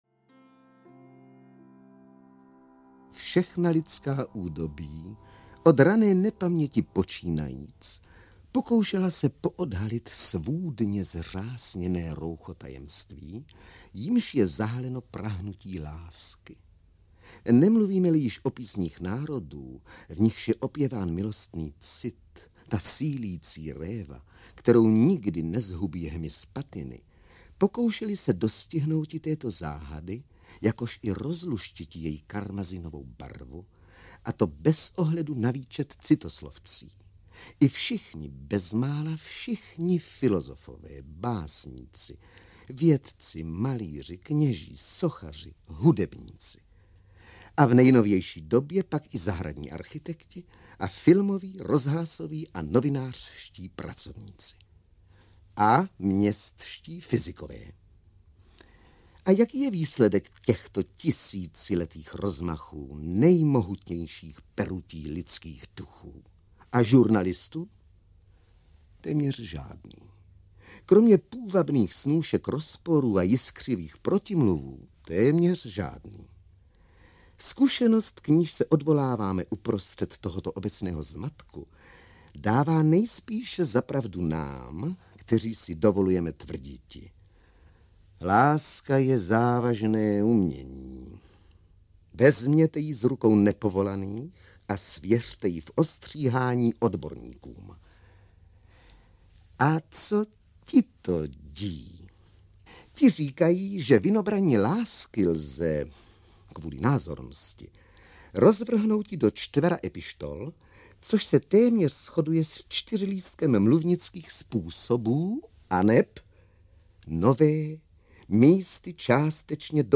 Ukázka z knihy
A tak je tu nová řada mluveného slova určená pouze pro digitální obchody "Supraphon vypravuje...", která bude obsahovat a nabízet povídky, fejetony, úryvky z knih, vzpomínky atd.V osmém dílu se můžete zaposlouchat do mluveného slova, jehož autory jsou klasici české literatury: Karel Konrád, Karel Čapek, Miroslav Horníček, Jaromír Tomeček a Bohumil Bezouška (s "Klubem Dobráků").Četbou či vyprávěním potěší hlasy neméně slavných a zvučných jmen hereček Jiřiny Petrovické a Libuše Švormové, herců Miloše Kopeckého, Josefa Pivce, Josefa Somra a Jaroslava Štercla. Svoje autorská díla čte Miroslav Horníček, v "Klubu dobráků" uslyšíte vzpomínky nejen Bohumila Bezoušky a Eduarda Kohouta ale i dalších.
• InterpretMiroslav Doležal, Eduard Kohout, Radovan Lukavský, Luděk Munzar, Jaroslav Štercl, Ladislav Pešek, František Filipovský, Bohumil Bezouška, Miloš Kopecký, Jiřina Petrovická, Libuše Švormová, Miroslav Horníček, Jan Pivec, Josef Somr